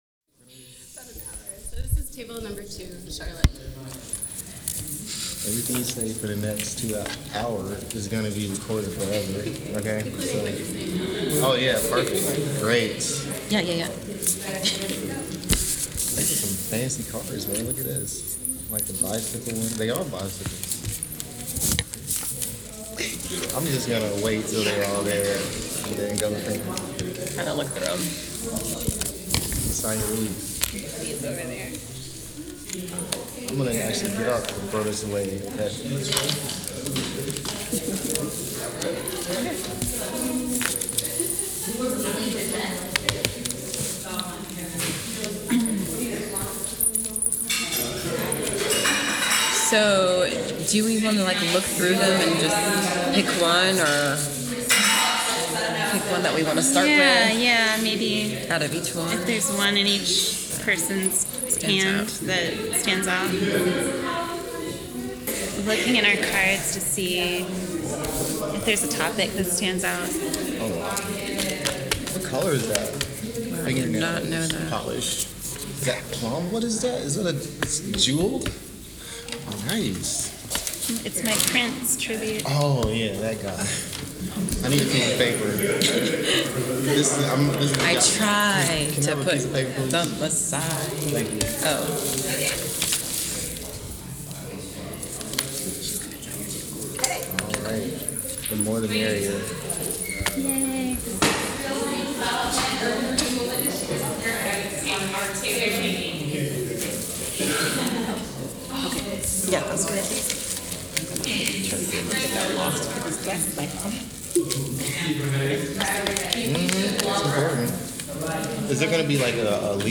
sound recording-nonmusical
oral history